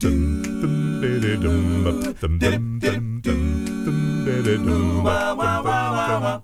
ACCAPELLA 9C.wav